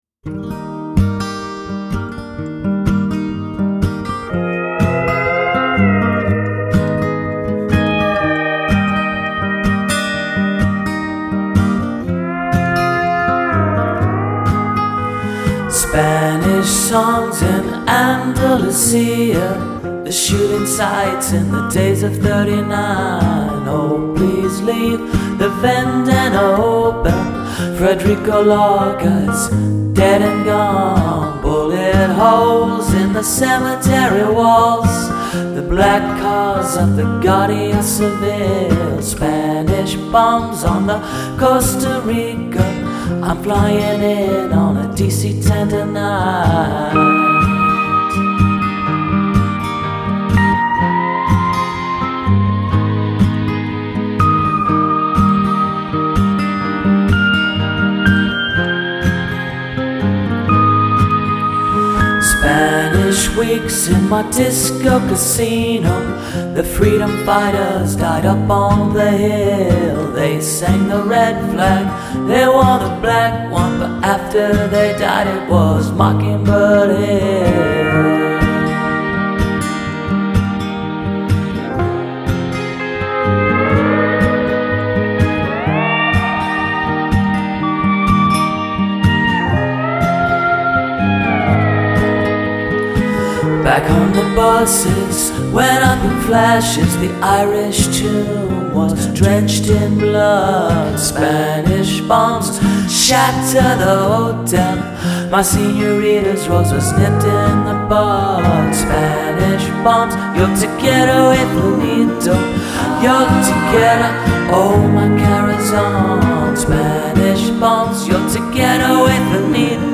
a country type instrumentation and sparse arrangement
at a slower tempo